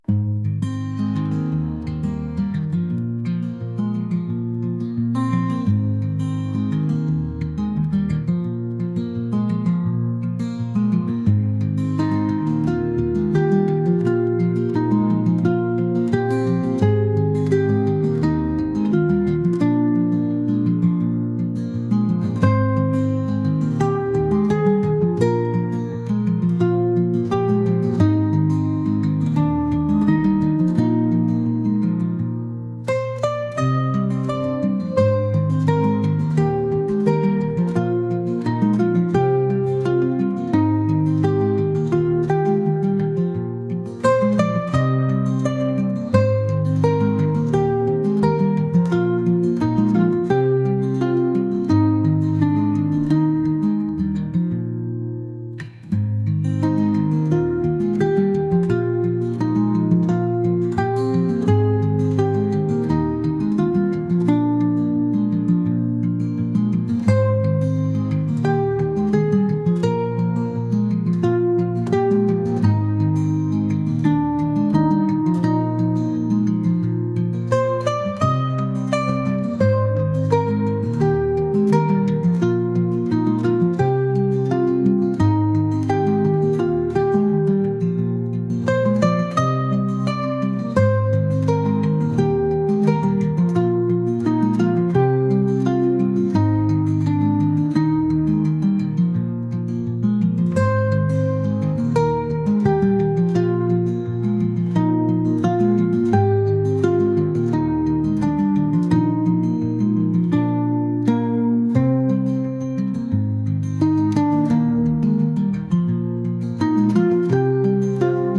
indie | folk | acoustic